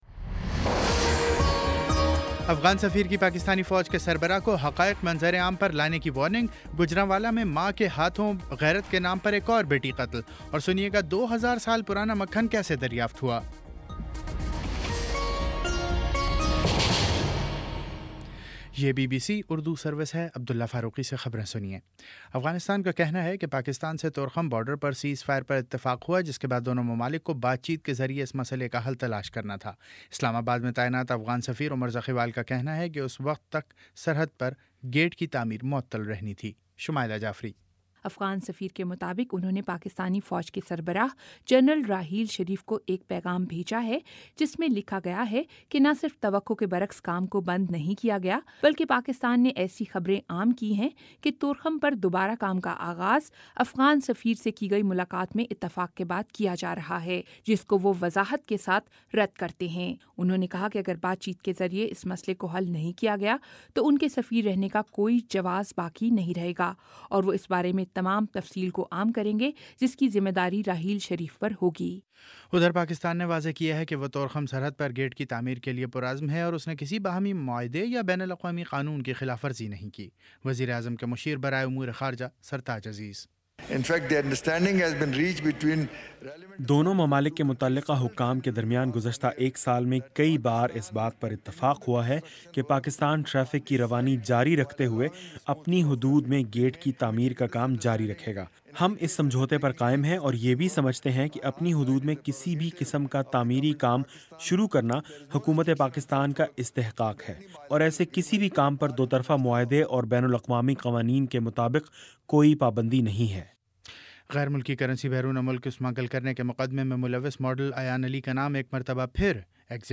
جون 16 : شام سات بجے کا نیوز بُلیٹن